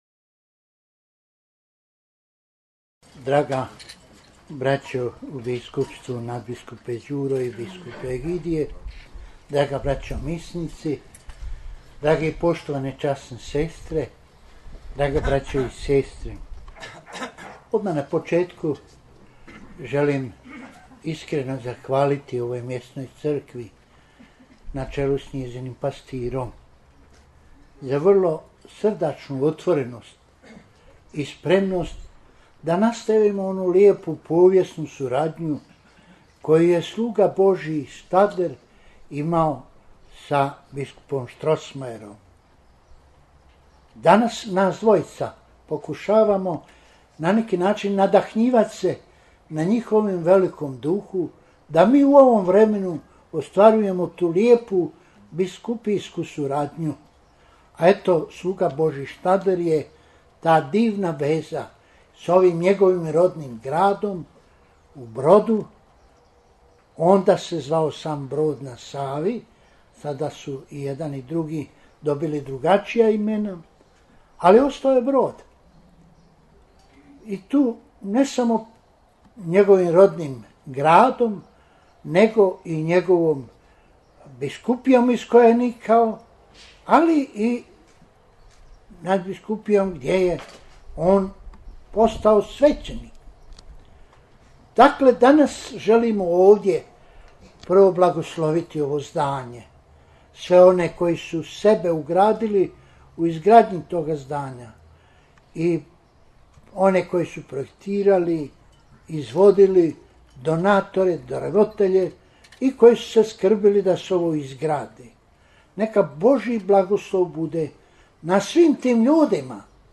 Stadlerov centar i sestarski Samostan blagoslovio je kardinal Vinko Puljić, nadbiskup metropolit vrhbosanski, tijekom svečanog Euharistijskog slavlja koje je slavio u zajedništvu s mons. Đurom Hranićem, đakovačko-osječkim nadbiskupom, i gradišćanskim Hrvatom mons. Egidijem Živkovićem, biskupom iz Željezna (Eisenstadt) u Austriji, te uz koncelebraciju 17 svećenika iz Hrvatske i Bosne i Hercegovine i asistenciju jednog đakona.